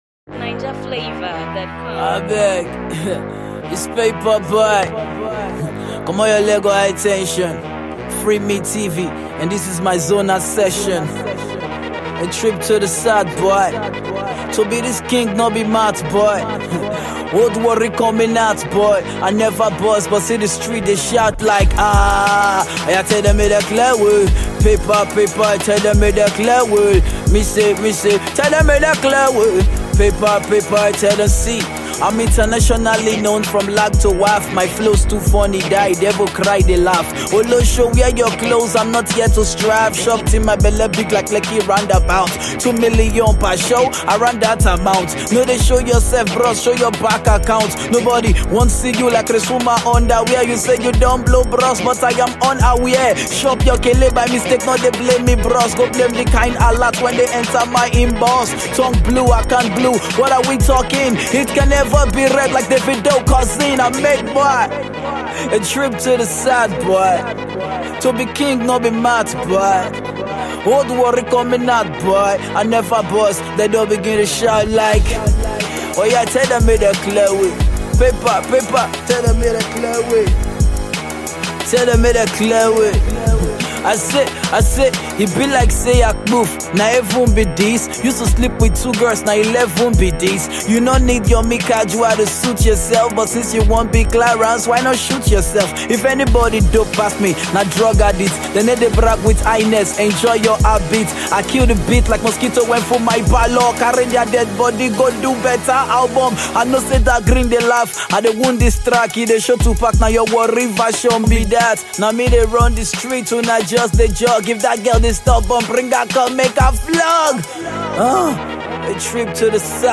Nigerian rap
deeply personal and introspective new track